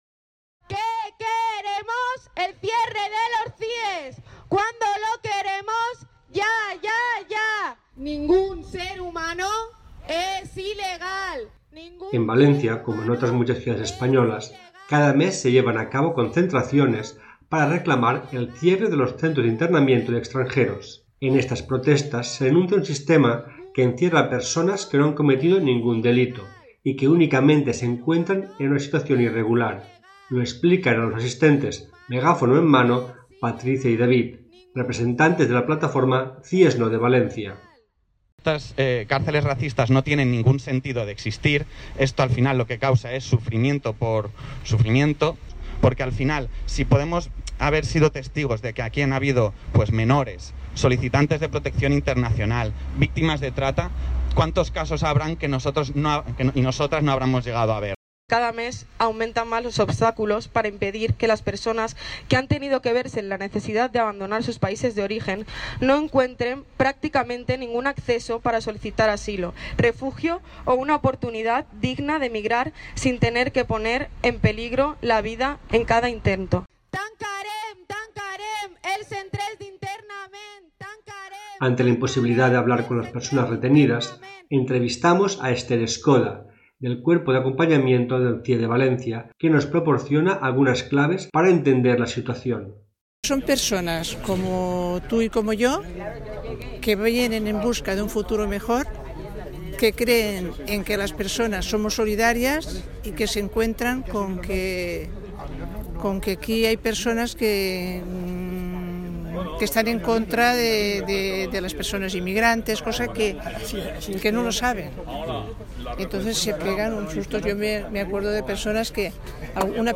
Ante la imposibilidad de hablar con las personas retenidas, entrevistamos a ciudadanía presente en la concentración y a representantes de la plataforma CIE’s No y del Cuerpo de Acompañamiento del CIE de Valencia.
reportaje radiofónico.
Reportaje-concentraciones-por-el-cierre-del-Centro-de-Internamiento-de-Extranjeros-de-Valencia.mp3